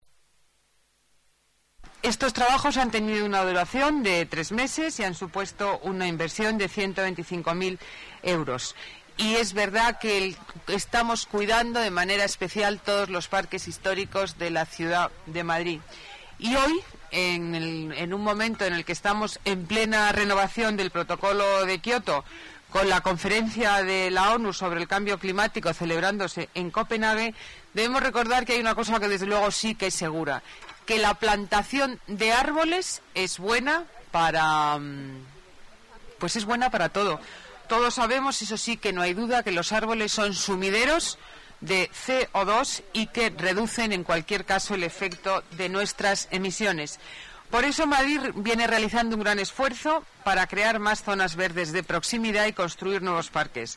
Nueva ventana:Declaraciones de la delegada de Medio Ambiente, Ana Botella